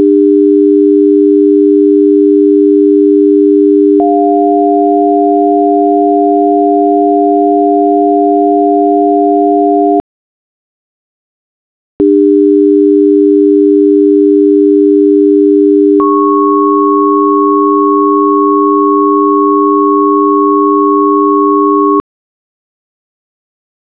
300 Hz/ 400 Hz
300 Hz/ 400 Hz combined with 702 Hz
300 Hz/ 400 Hz combined with 1102 Hz
Listen for the beats with 702 Hz and 1102 Hz.